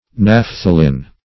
Search Result for " naphthalin" : The Collaborative International Dictionary of English v.0.48: Naphthalin \Naph"tha*lin\, Naphthaline \Naph"tha*line\, n. [F. naphthaline.]